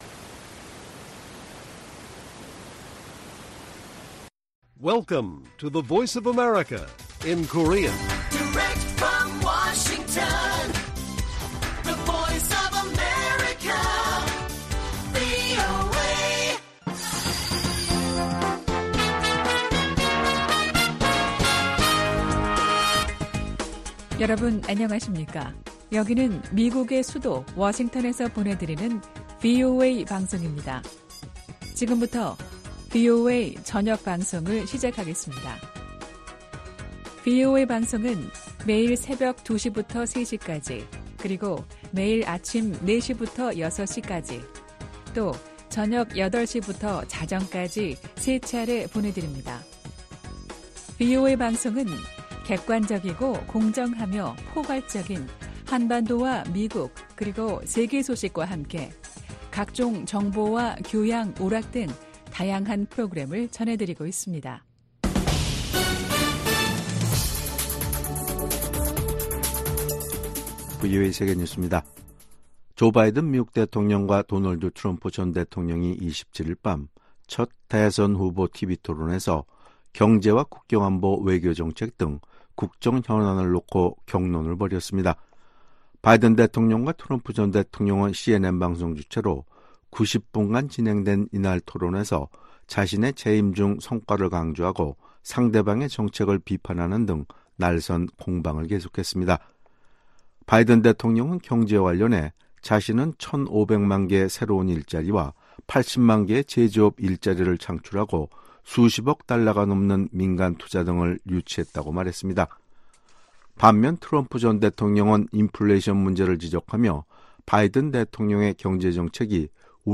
VOA 한국어 간판 뉴스 프로그램 '뉴스 투데이', 2024년 6월 28일 1부 방송입니다. 조 바이든 대통령과 도널드 트럼프 전 대통령이 첫 대선 후보 토론회에 참석해 날선 공방을 벌였습니다. 미국 정부는 한국 정치권에서 자체 핵무장론이 제기된 데 대해 현재 한국과 공동으로 확장억제를 강화하고 있다고 강조했습니다. 미 국무부 고위 관리가 최근 심화되고 있는 북한과 러시아 간 협력에 대한 중대한 우려를 나타냈습니다.